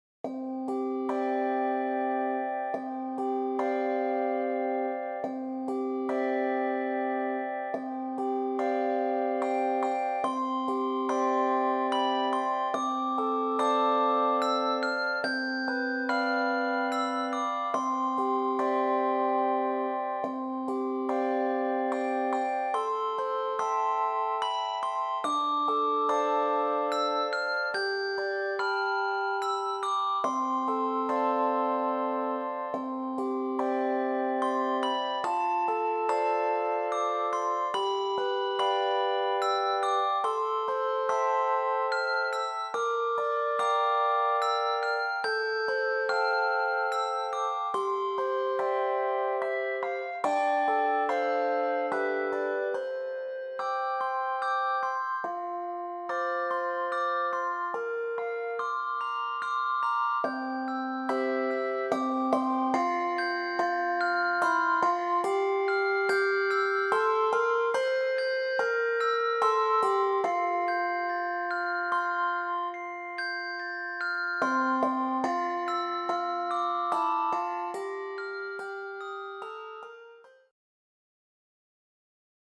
Voicing: Handbells Quartet